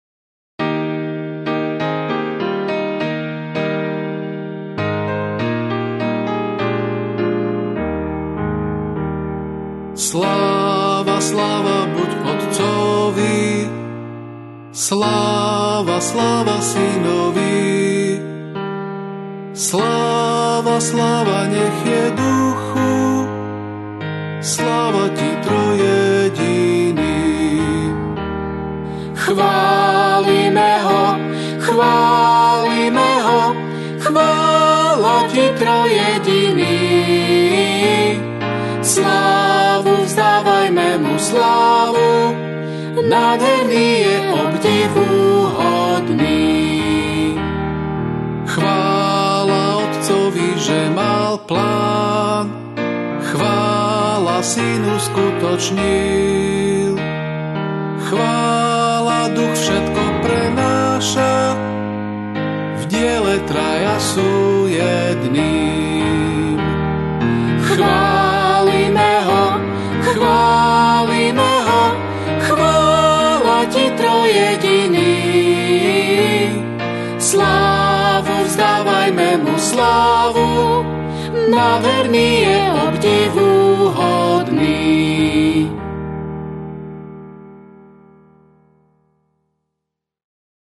降E大調